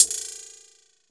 Index of /90_sSampleCDs/USB Soundscan vol.20 - Fresh Disco House I [AKAI] 1CD/Partition C/09-HH CLOSED